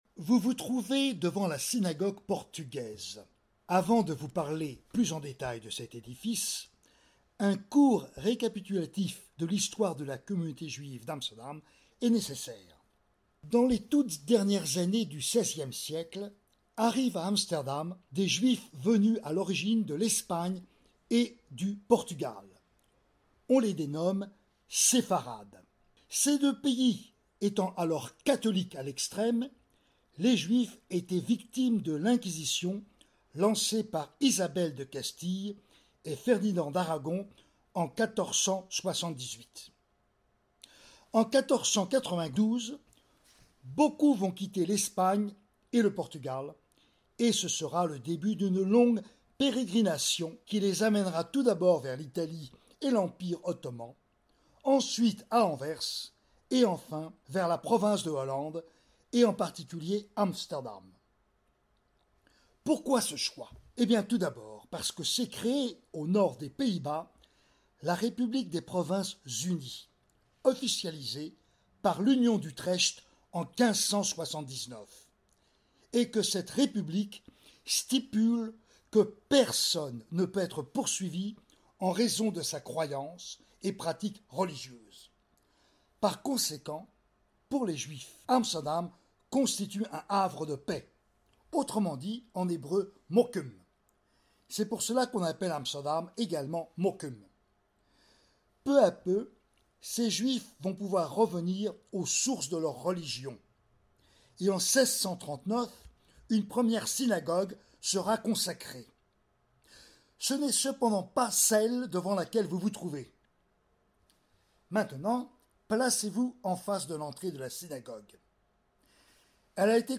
Bienvenue à notre visite guidée mystère – Synagogue Portugaise